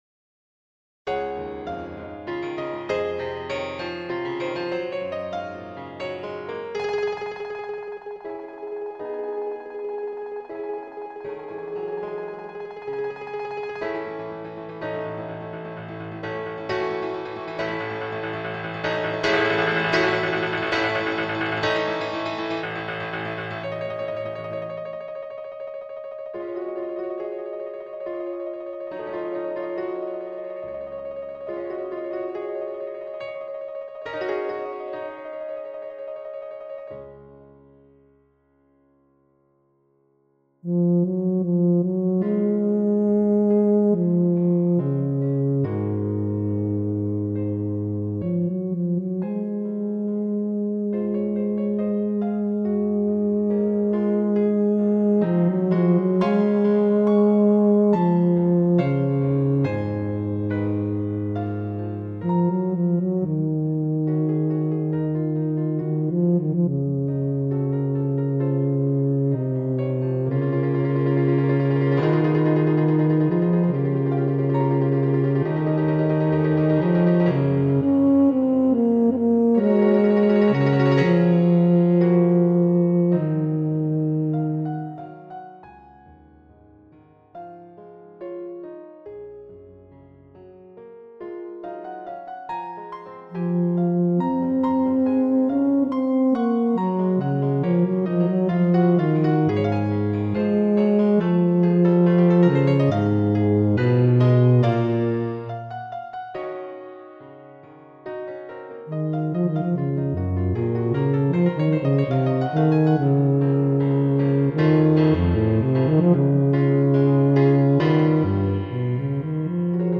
Un solo movimiento.